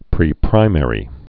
(prē-prīmĕrē, -mə-rē)